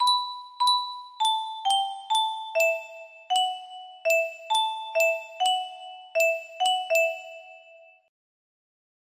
Rotten Rose music box melody
Full range 60